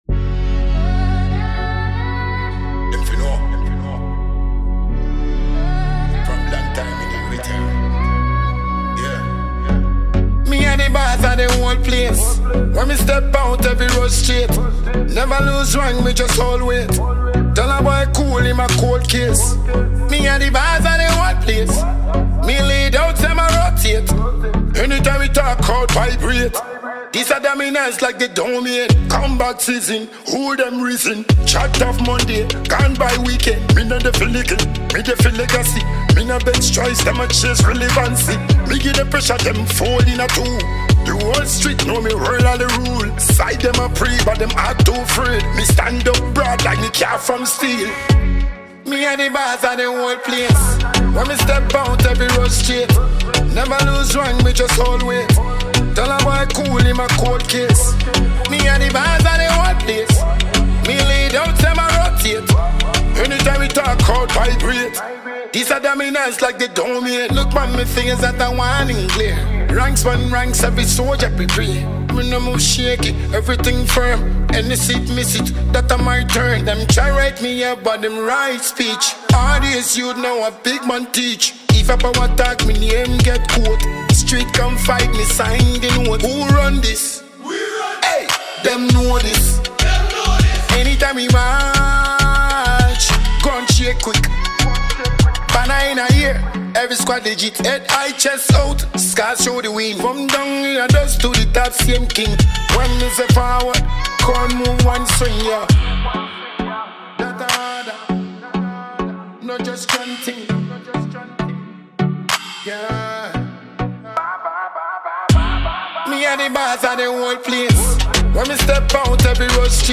high-energy anthem